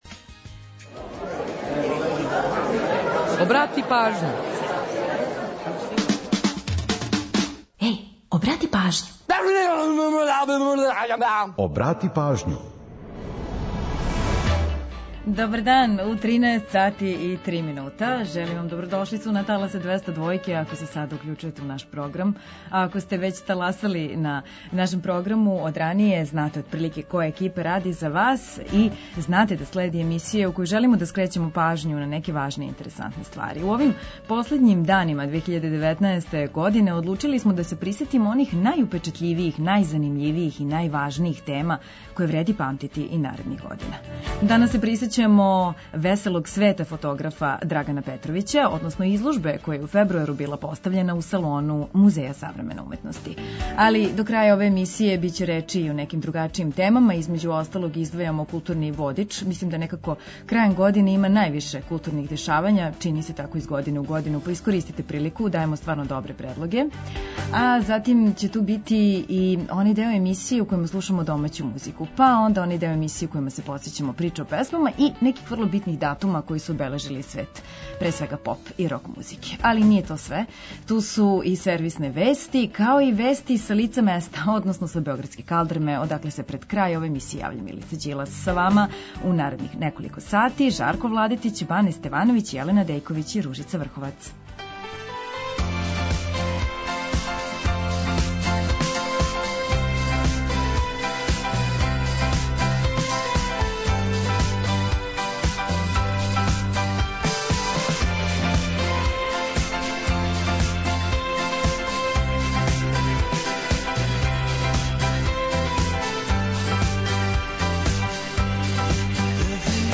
Чућете „Приче о песмама”, пола сата музике из Србије и региона, а упозоравамо и на евентуалне саобраћајне гужве.